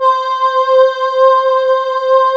Index of /90_sSampleCDs/USB Soundscan vol.28 - Choir Acoustic & Synth [AKAI] 1CD/Partition D/14-AH VOXST